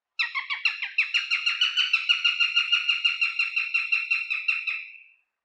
ハイタカ｜日本の鳥百科｜サントリーの愛鳥活動
「日本の鳥百科」ハイタカの紹介です（鳴き声あり）。
haitaka_ch.mp3